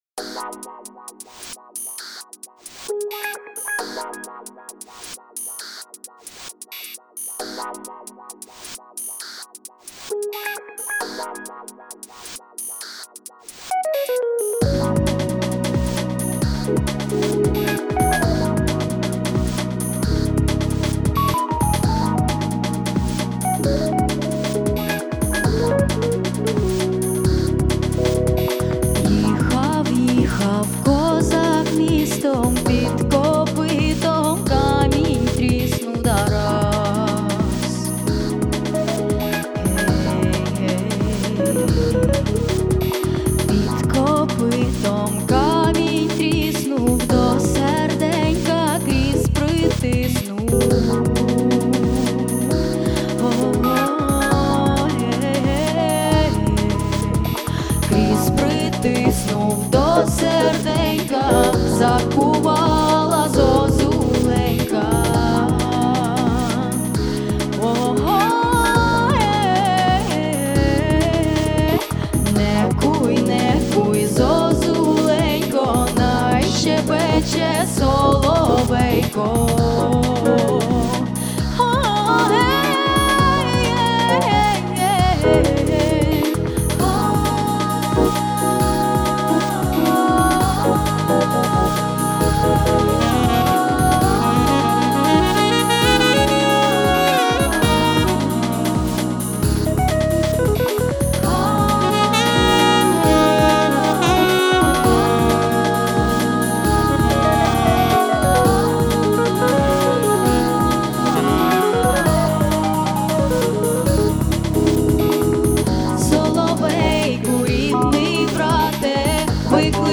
джаз, Етно, поп, співачка